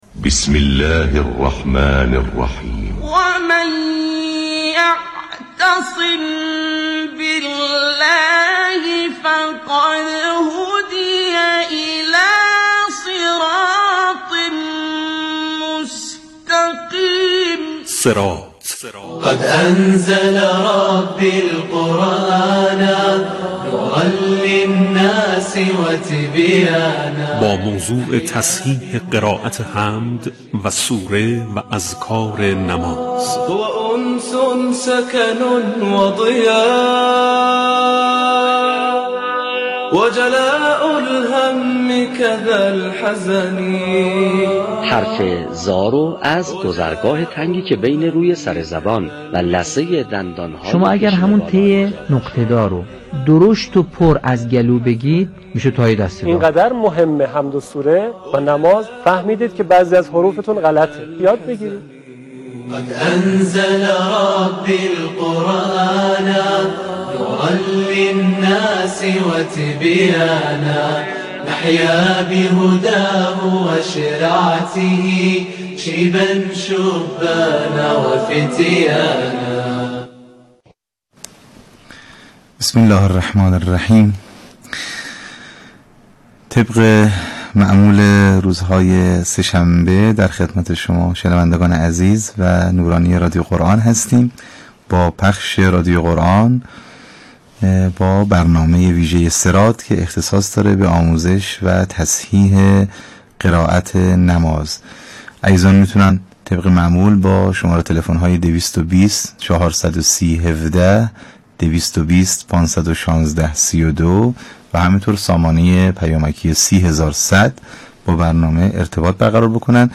قرائت حمد و اذکار نماز را در تماس تلفنی با رادیو قرآن تصحیح کنید + صوت
برنامه «صراط» رادیو قرآن با محوریت آموزش و تصحیح قرائت نماز روزهای سه‌شنبه به صورت زنده پخش می‌شود.